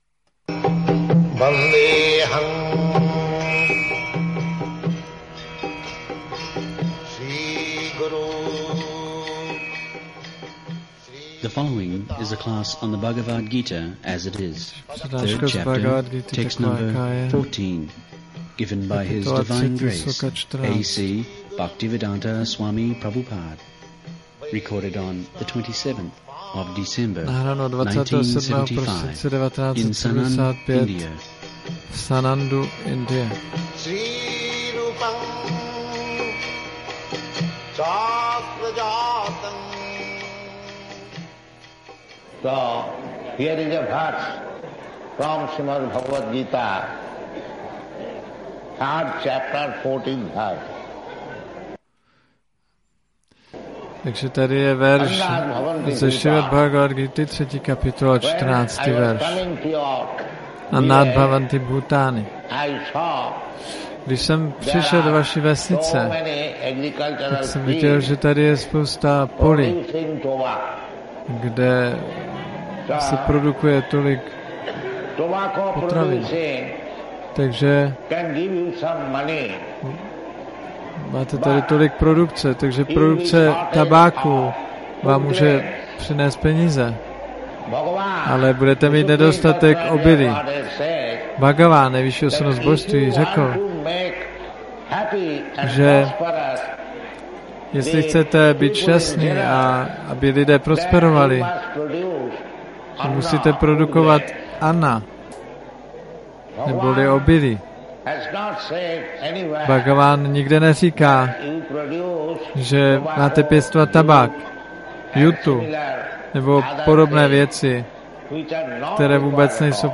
1975-12-27-ACPP Šríla Prabhupáda – Přednáška BG-3.14 Sannand